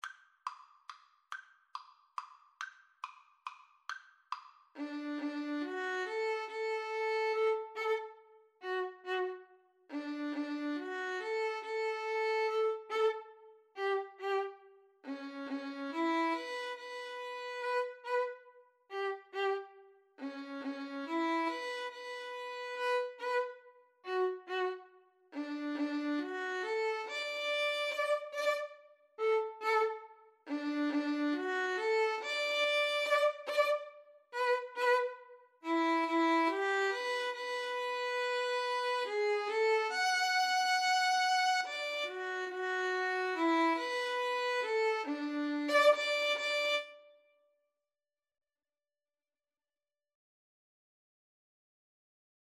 3/4 (View more 3/4 Music)
=140 Slow one in a bar